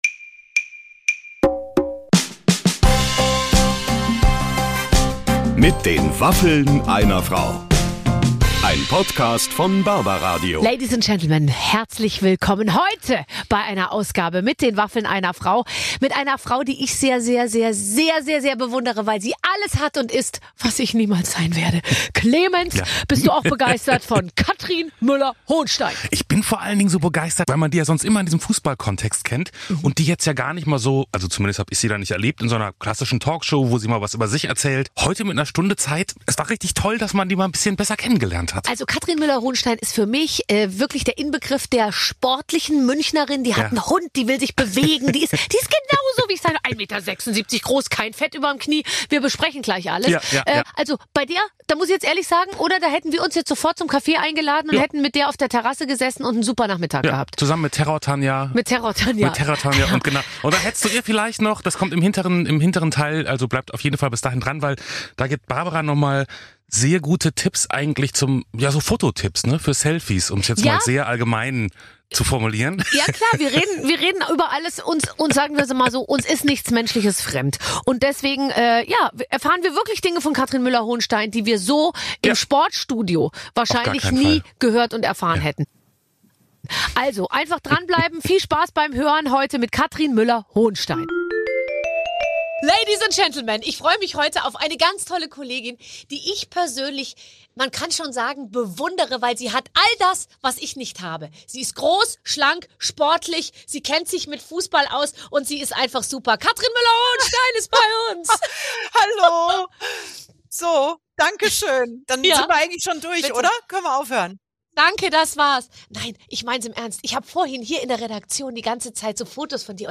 Zusammen mit Barbara Schöneberger plaudert sie über ihren Hund "Terror-Tanja", bei dem sie mit der Erziehung komplett versagt hat. Außerdem geht es natürlich auch um Fußball und wieso sie den Begriff "Männerdomäne" überhaupt nicht ausstehen kann!